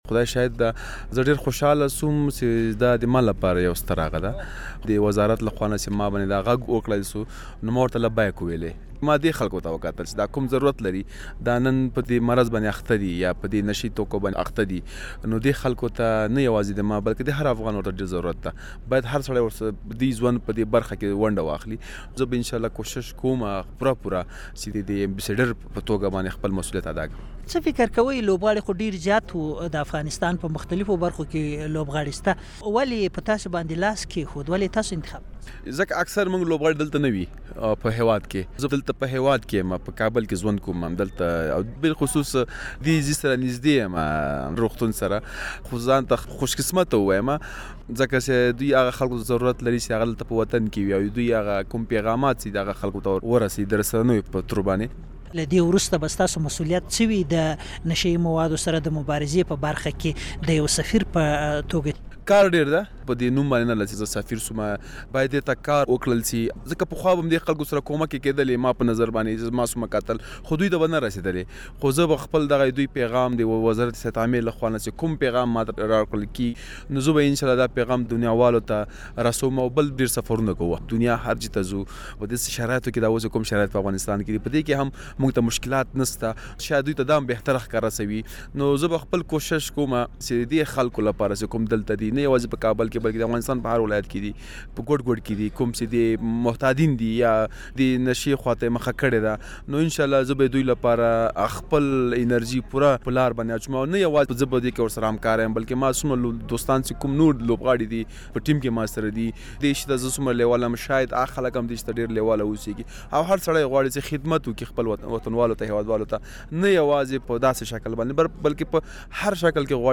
په دې اړه مو له ده سره مرکه کړې او په پيل کې مو پوښتلی چې په دې دومره ډېرو لوبغاړو کې ولې دی د سفیر په توګه وټاکل شو؟